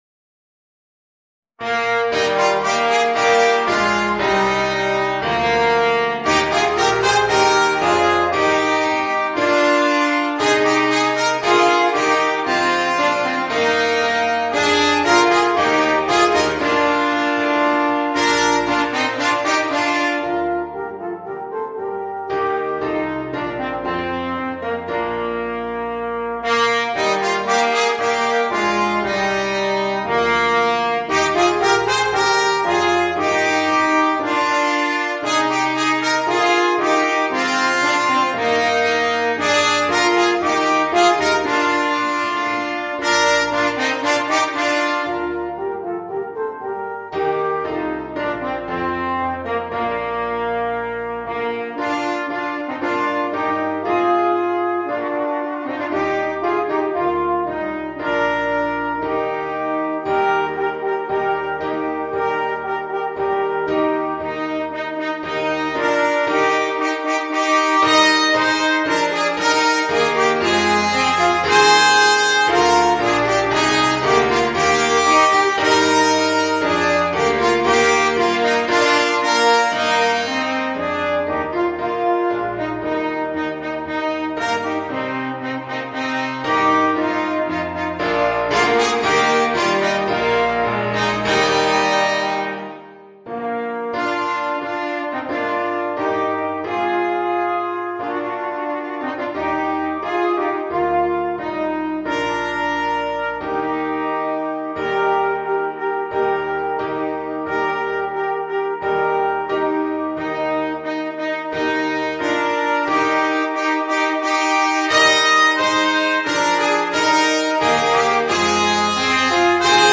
Voicing: Trumpet